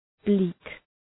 Προφορά
{bli:k}